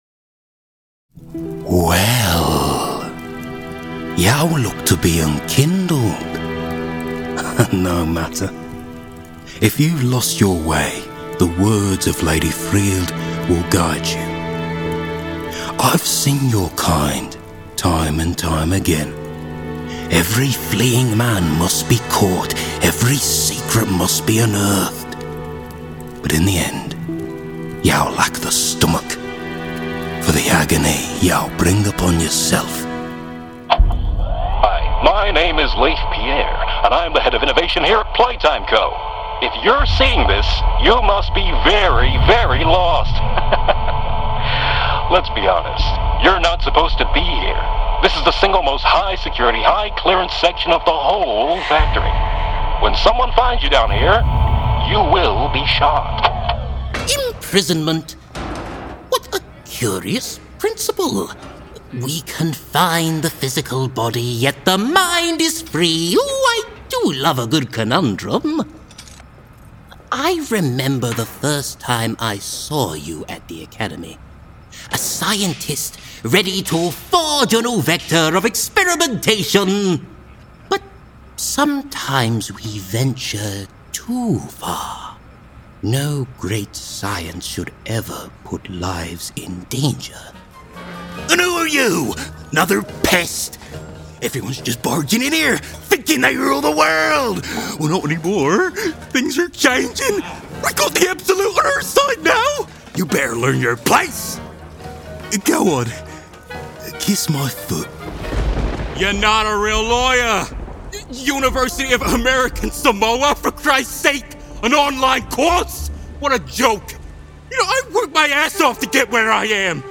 Video Game Showreel
Male
West Midlands
Playful